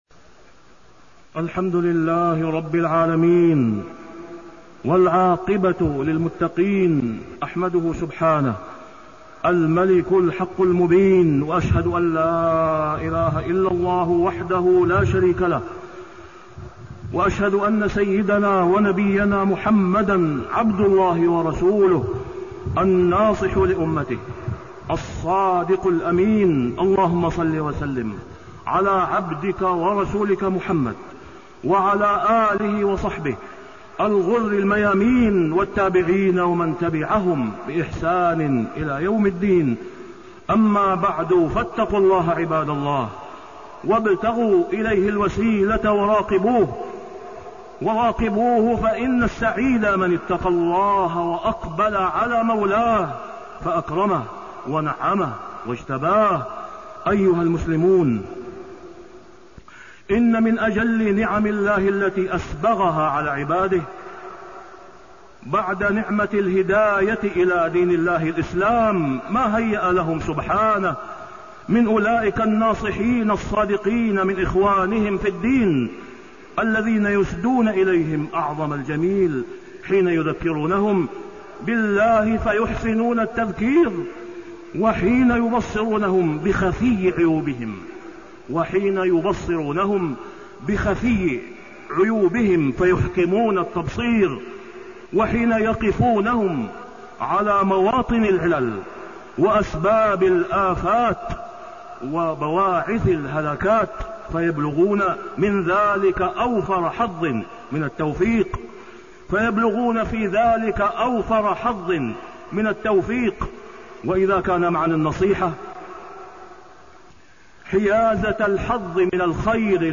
تاريخ النشر ٨ ربيع الأول ١٤٣٢ هـ المكان: المسجد الحرام الشيخ: فضيلة الشيخ د. أسامة بن عبدالله خياط فضيلة الشيخ د. أسامة بن عبدالله خياط النصيحة وأهميتها